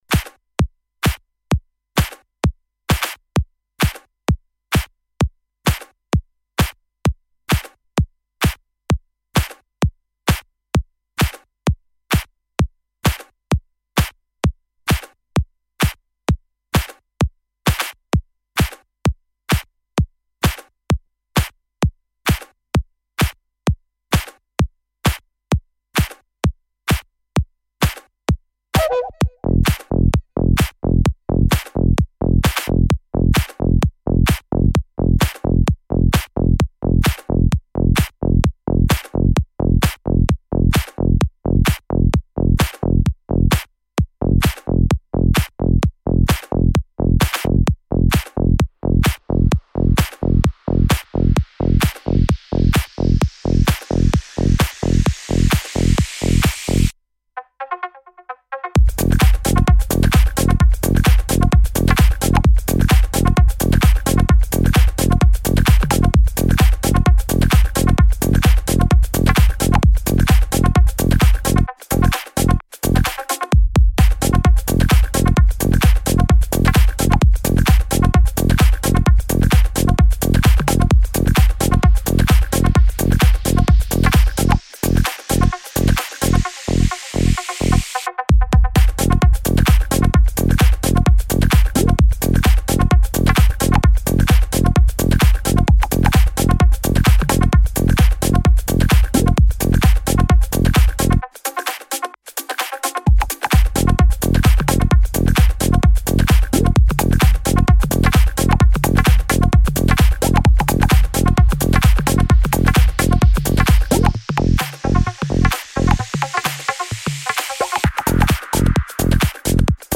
stormy noises for windy days